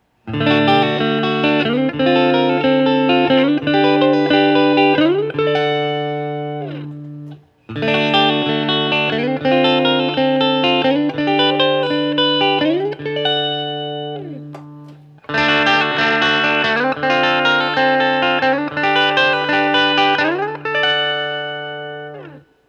Every sound sample cycles from the neck pickup, to both, to the bridge pickup.
D-shape Chords
[/dropshadowbox]For these recordings I used my normal Axe-FX Ultra setup through the QSC K12 speaker recorded into my trusty Olympus LS-10.